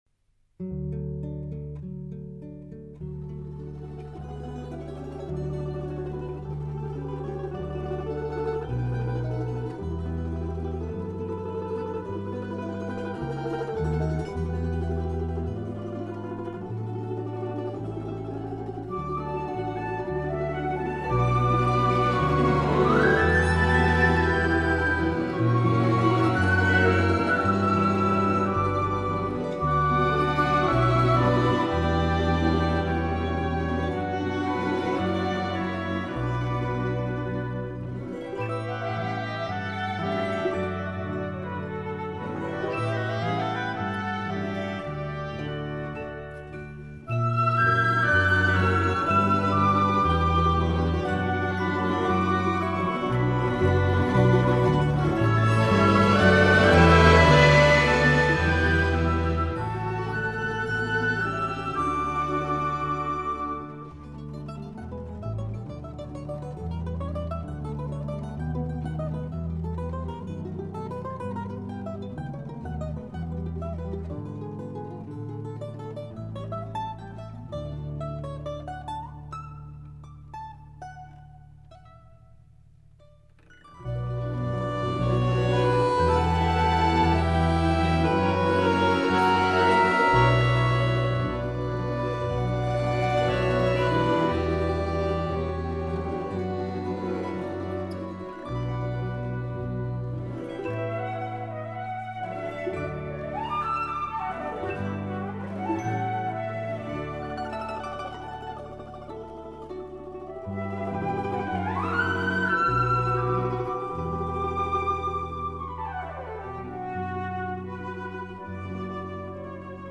Russian Folk Instruments Soloist's Band